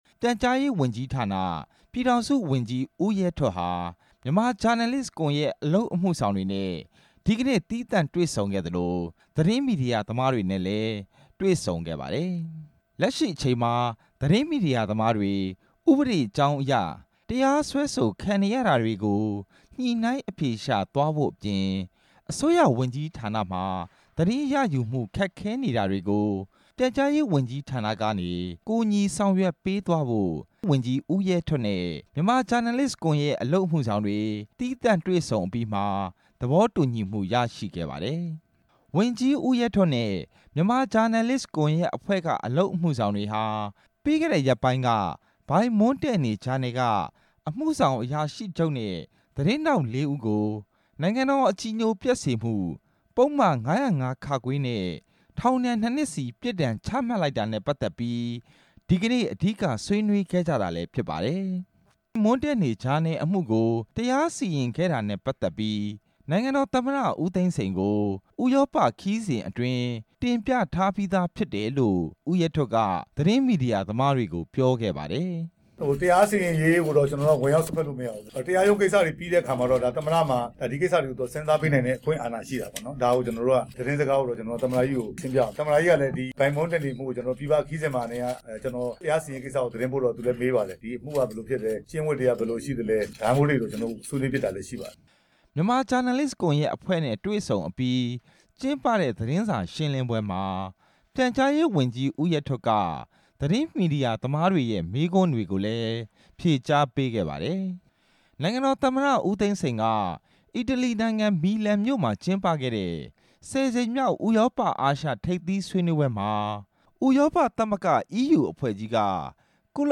ဦးရဲထွဋ် သတင်းစာရှင်းလင်းပွဲ
ရန်ကုန်မြို့ မြန်မာဂျာနယ်လစ်ကွန်ရက်ရုံးခန်းမှာ ဒီနေ့ ကျင်းပခဲ့တဲ့ သတင်းစာရှင်းလင်းပွဲမှာ ပြည်ထောင်စု ဝန်ကြီး ဦးရဲထွဋ်က သတင်းမီဒီယာသမားတွေကို အခုလိုပြောခဲ့တာဖြစ်ပါတယ်။